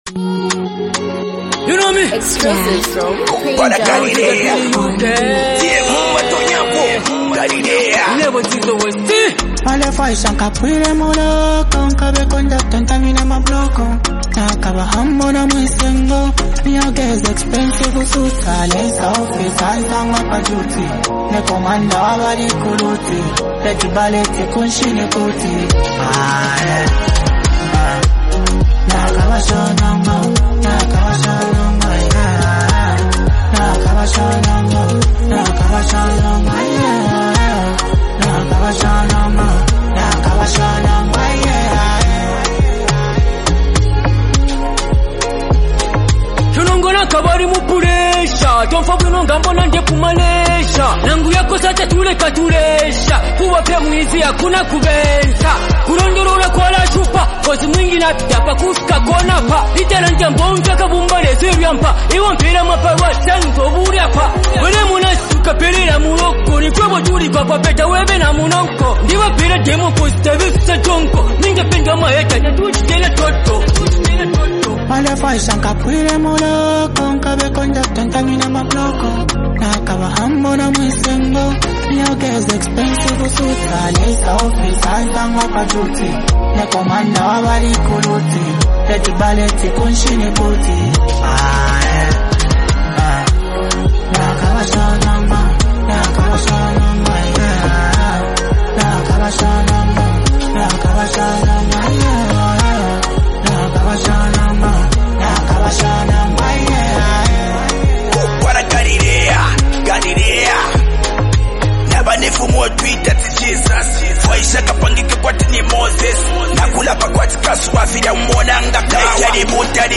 high-energy street anthem